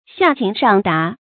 注音：ㄒㄧㄚˋ ㄑㄧㄥˊ ㄕㄤˋ ㄉㄚˊ
下情上達的讀法